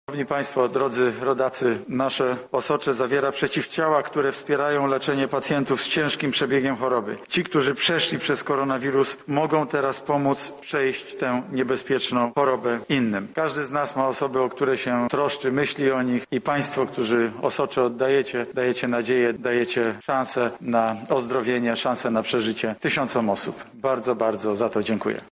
Oddanie osocza to nie tylko szlachetny czyn, to także akt, który może uratować życie wielu osób– mówił podczas dzisiejszej konferencji premier Mateusz Morawiecki.
Jednak prawdziwą nagrodą jest to kiedy ktoś oddaje osocze, może je podarować choremu– mówi premier Mateusz Morawiecki i zaapelował do ozdrowieńców: